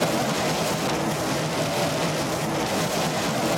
jet_boost.mp3